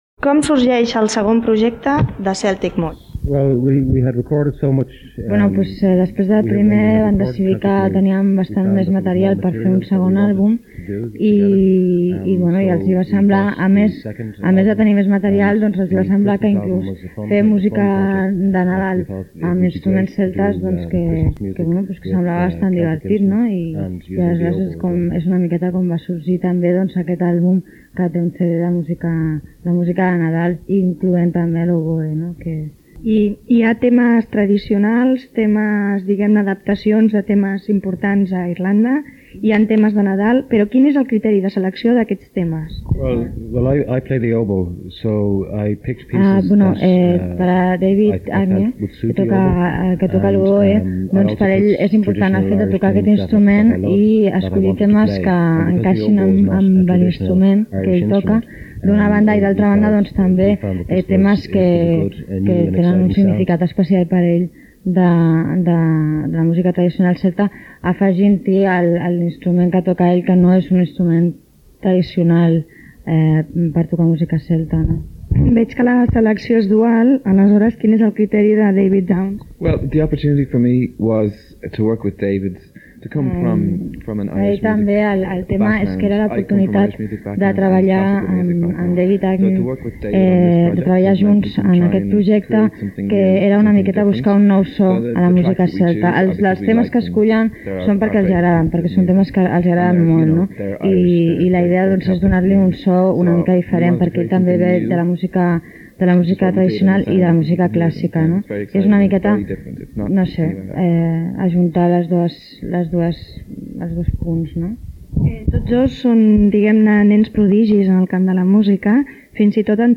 Entrevista als integrants del grup musical Celtic Moods
Musical